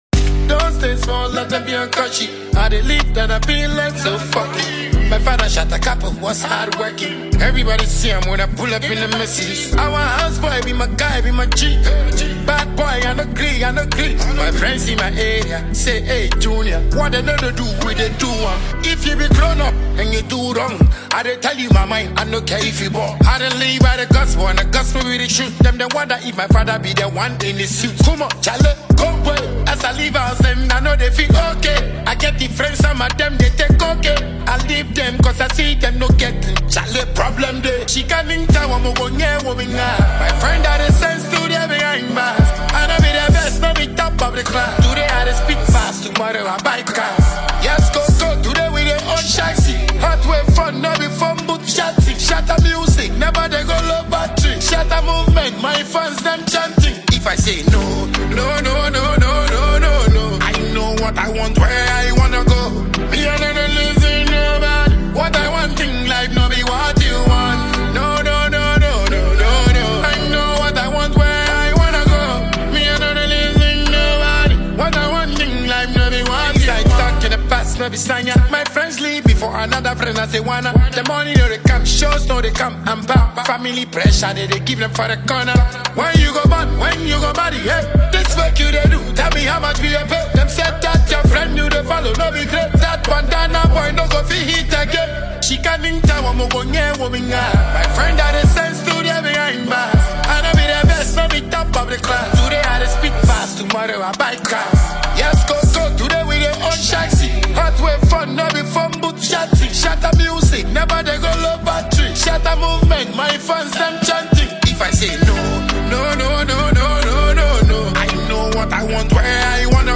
Defiant and Anthemic New Single